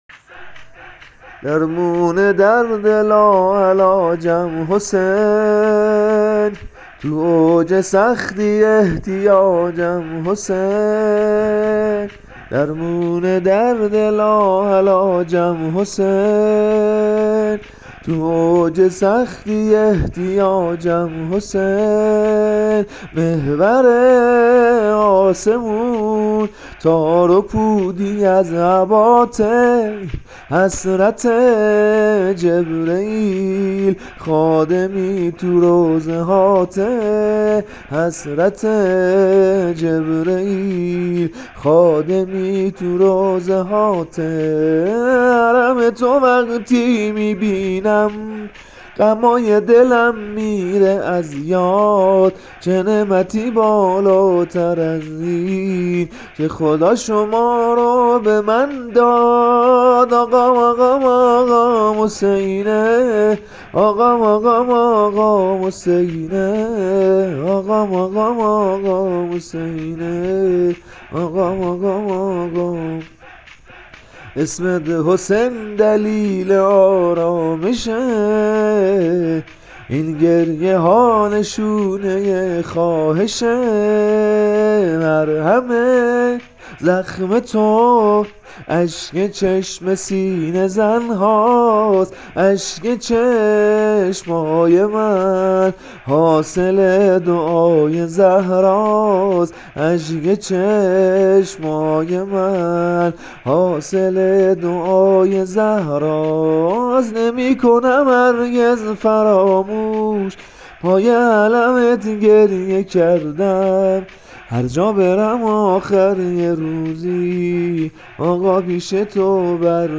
شعر شور امام حسین (ع) -(درمون درد لاعلاجم حسین)